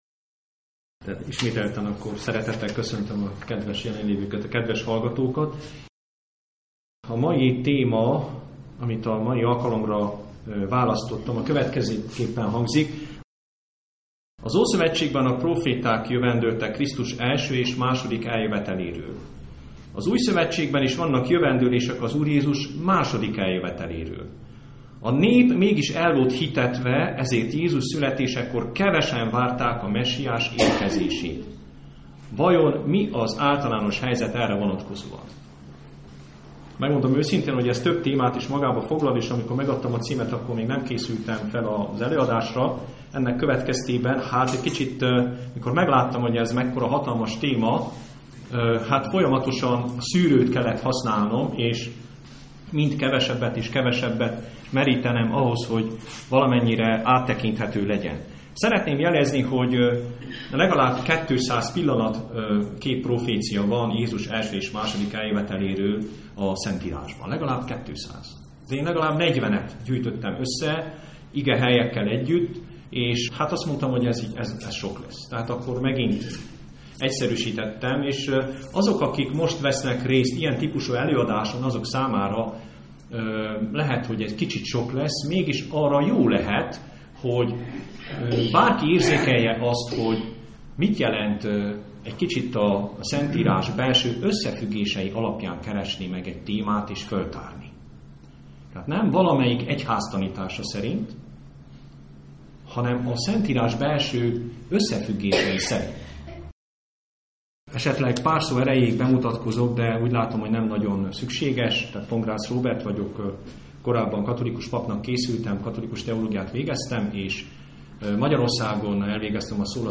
Az első előadás hanganyagát itt találod meg!